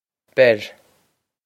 Beir Ber Bring/Catch/Bear
This is an approximate phonetic pronunciation of the phrase.